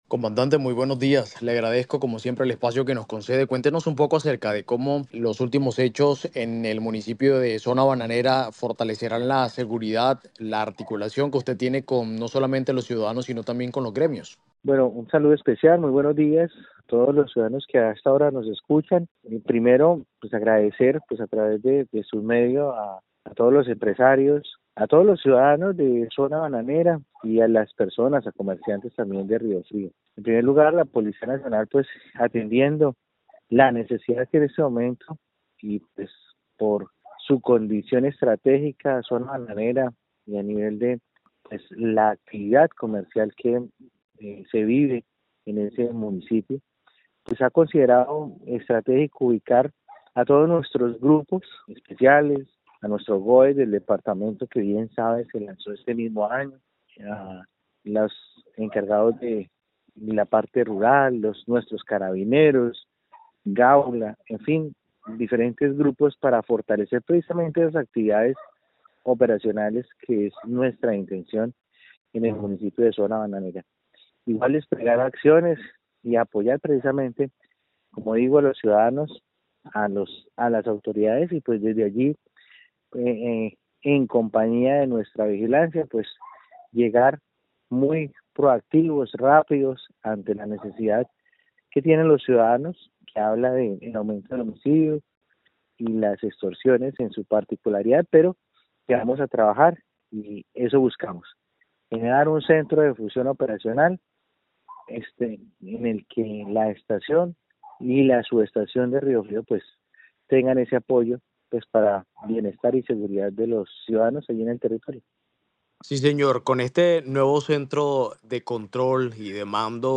COMANDANTE DE POLICÍA, CORONEL JAVIER DUARTE